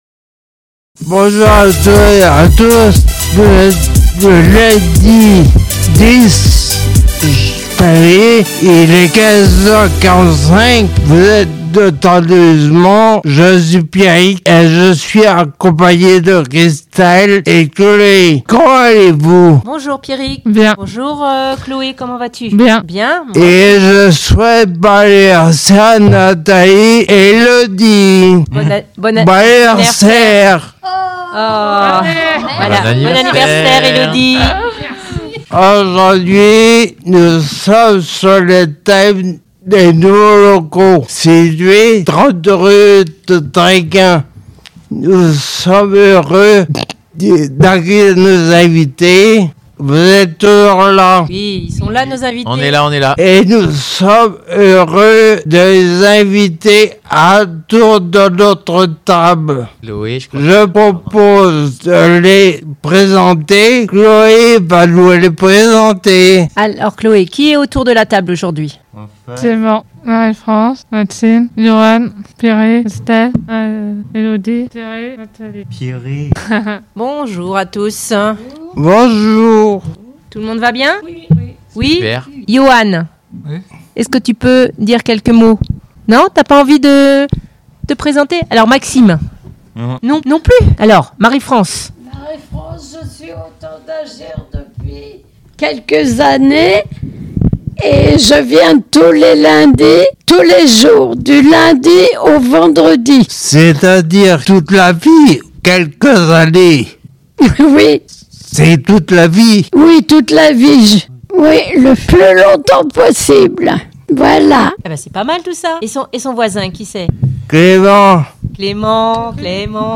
Le groupe est allé à la rencontre des personnes accueillies et des professionnels du Temps d’Agir pour parler des nouveaux locaux, un moment riche en partages !